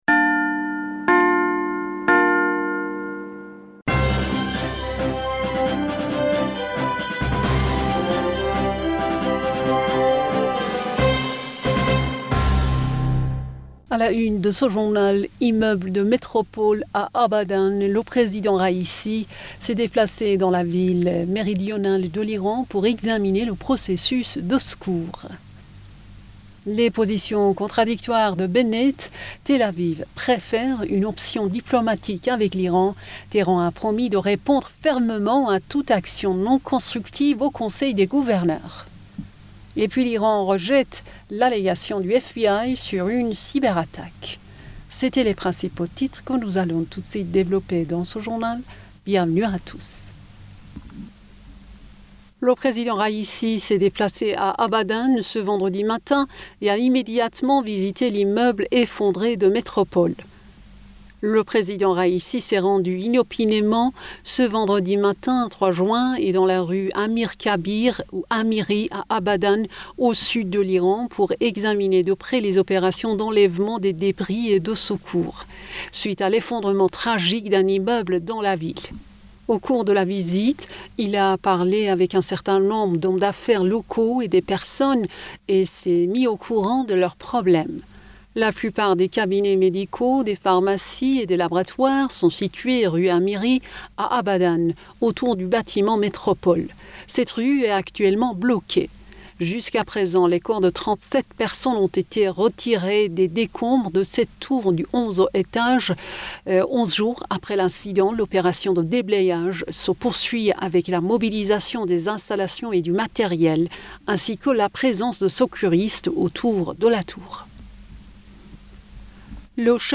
Bulletin d'information Du 03 Juin